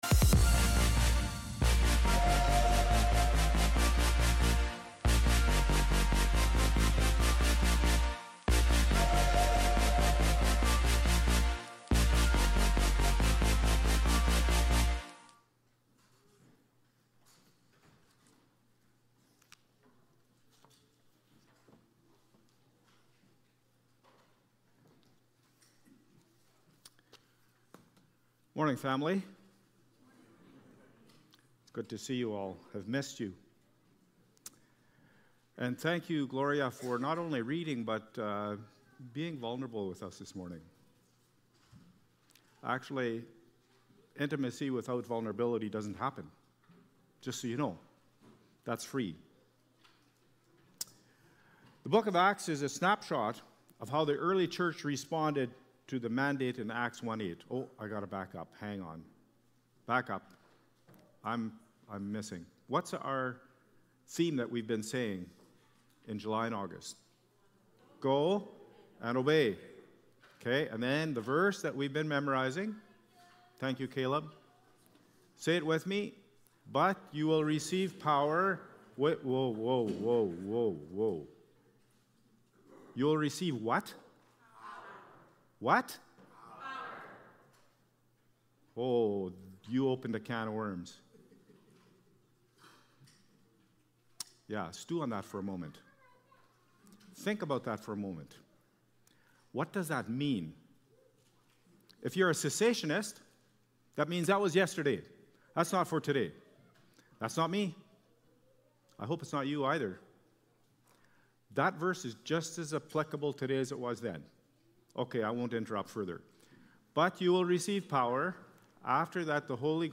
July-27-Worship-Service.mp3